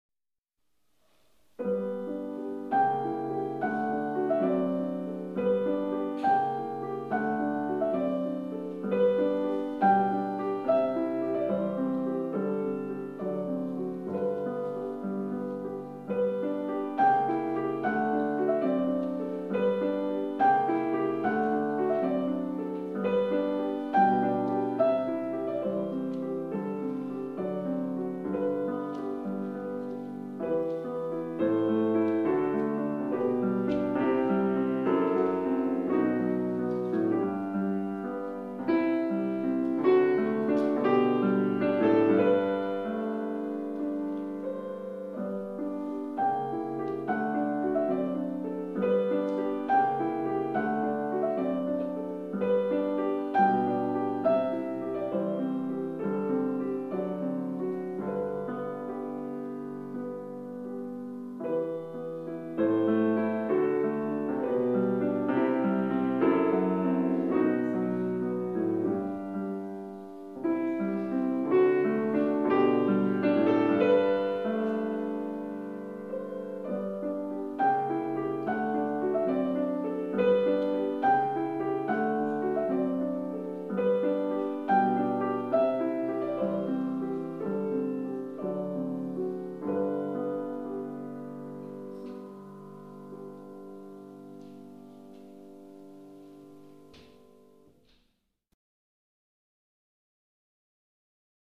Here's some of my piano recordings.